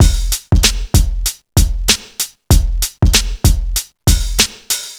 Free drum groove - kick tuned to the F note. Loudest frequency: 1318Hz
• 96 Bpm Breakbeat Sample F Key.wav
96-bpm-breakbeat-sample-f-key-kSV.wav